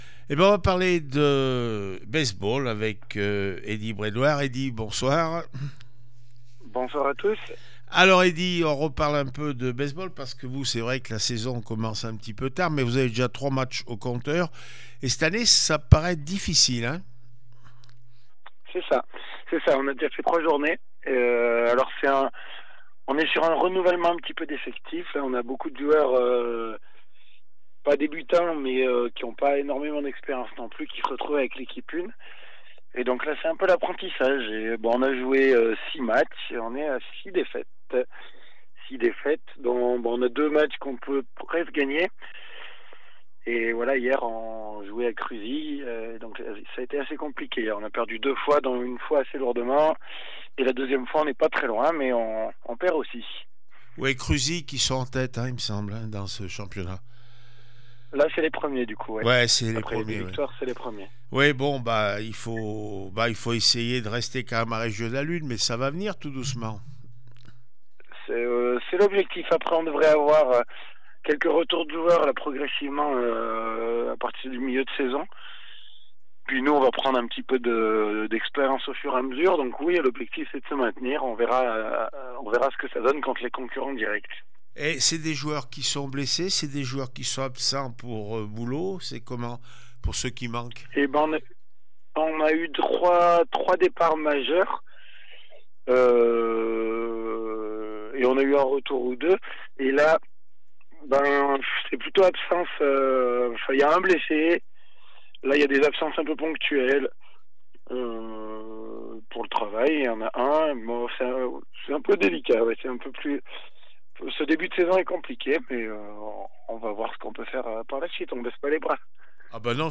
base ball cruzille – les Shark du puy réaction après match 31-3 & 14-4